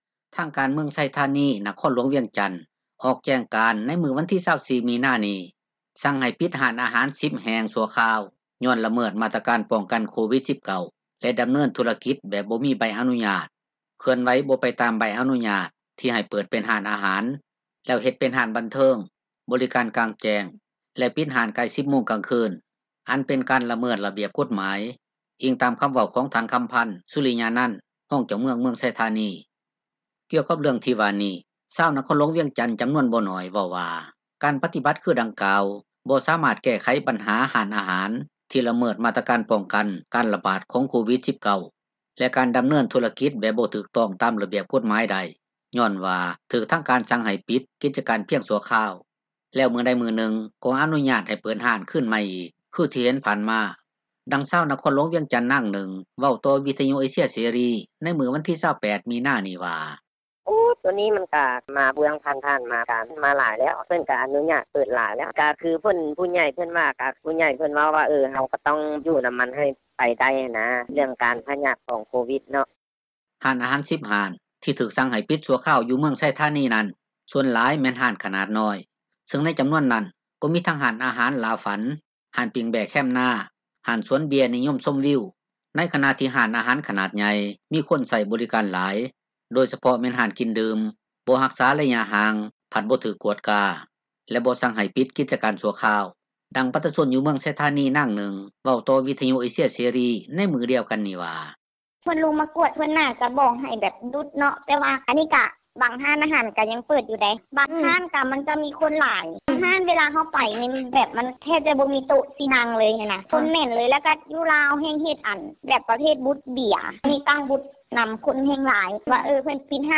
ດັ່ງຊາວນະຄອນຫລວງວຽງຈັນນາງນຶ່ງ ເວົ້າຕໍ່ວິທຍຸເອເຊັຽເສຣີໃນມື້ວັນທີ 28 ມິນານີ້ວ່າ:
ດັ່ງປະ ຊາຊົນຢູ່ເມືອງໄຊທານີນາງນຶ່ງ ເວົ້າຕໍ່ວິທຍຸເອເຊັຽເສຣີໃນມື້ດຽວກັນນີ້ວ່າ: